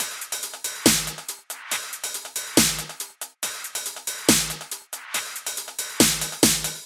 Drum Percussion Loops " Drum Loop Rock02 120 - 声音 - 淘声网 - 免费音效素材资源|视频游戏配乐下载